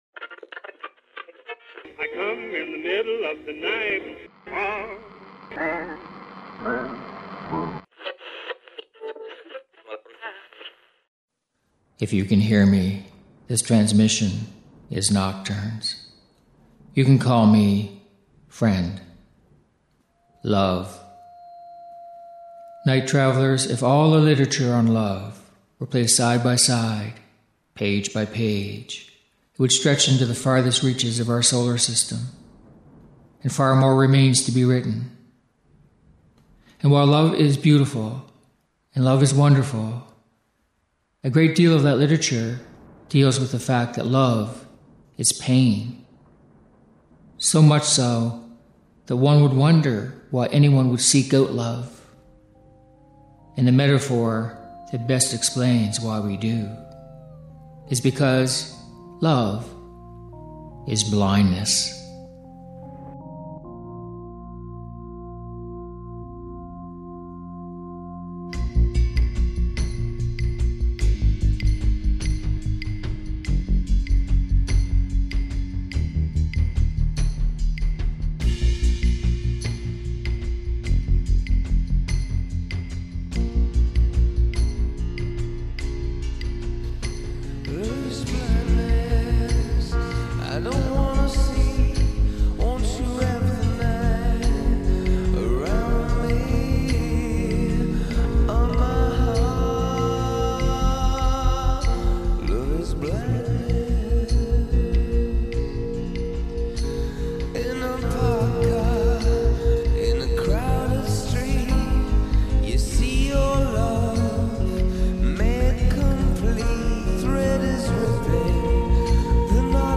Music for nighttime listening.